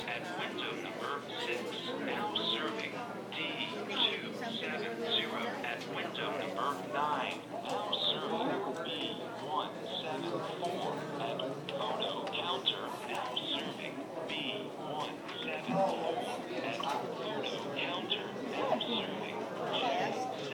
The Sounds of Madness (live from the AZ DMV)